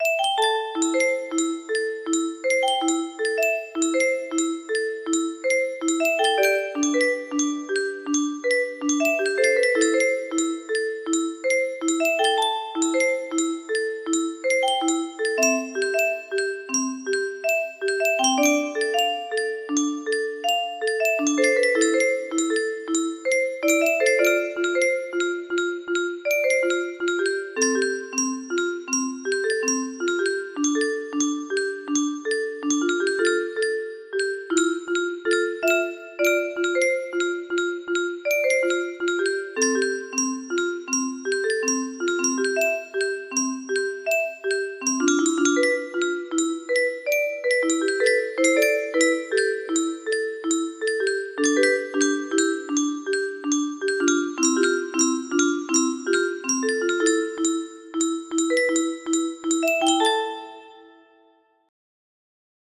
A greta carillon music box melody